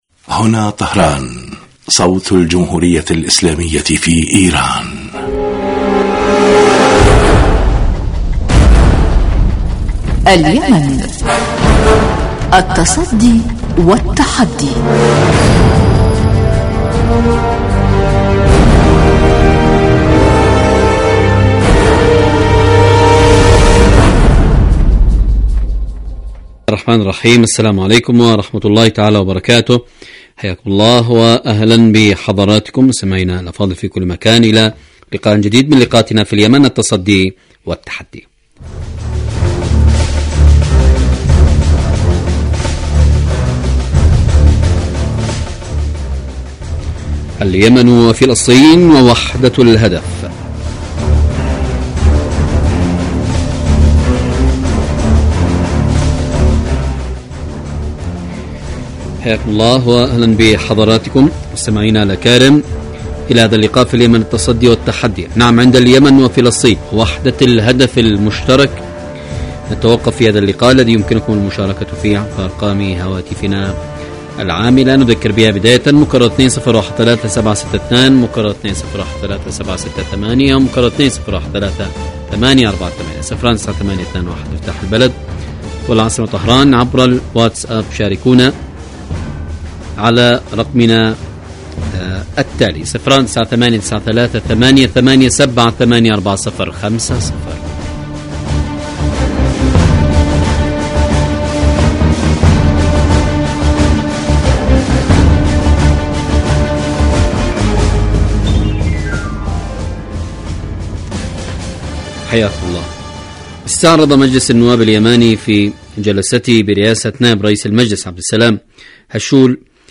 الكاتب والمحلل السياسي من سوريا.